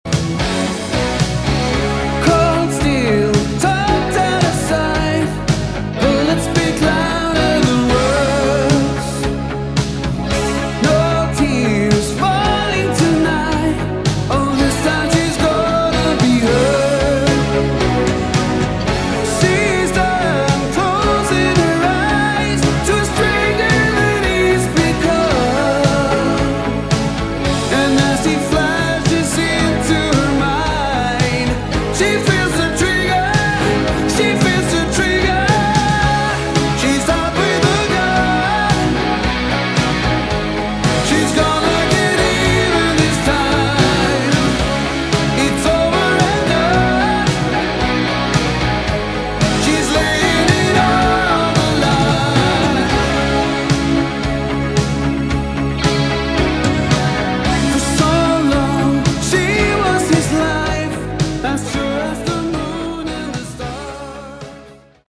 Category: AOR / Melodic Rock
lead, backing vocals
lead guitar
keyboards, piano
bass guitar